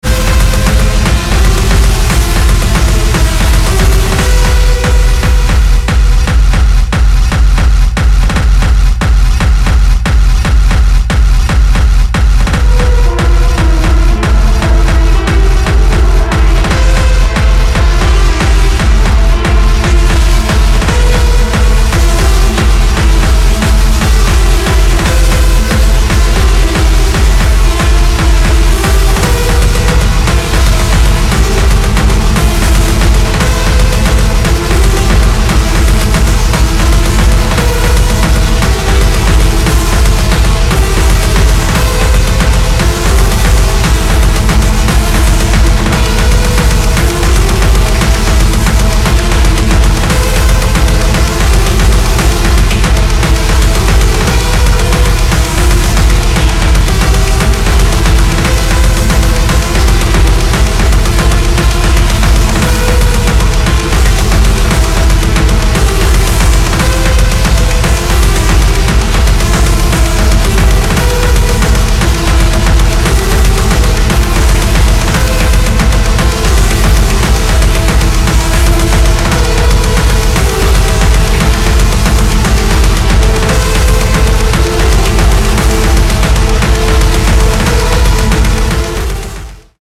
IDM/Electronica, Techno